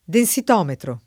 [ den S it 0 metro ]